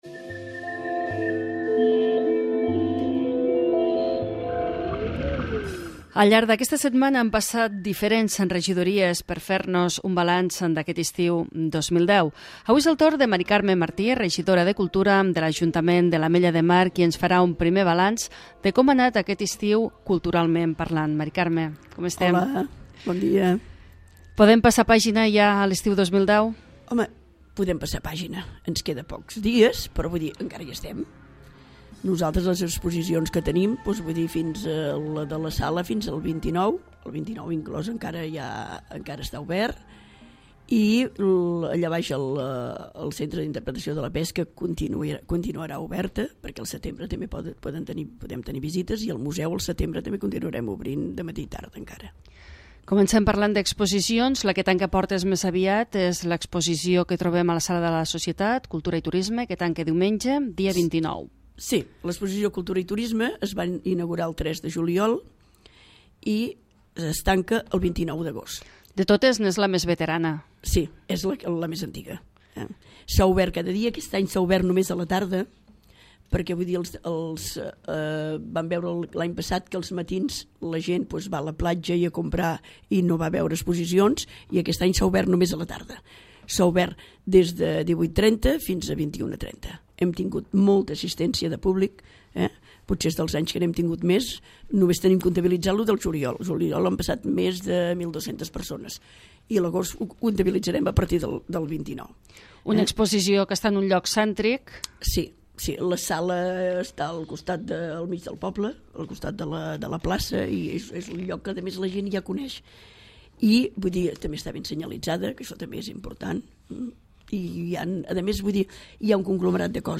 L'Entrevista
Amb Mª Carme Martí, regidora de cultura, de l'ajuntament de l'Ametlla de Mar, hem fet un primer balanç de com han anat aquest estiu, culturalment parlant.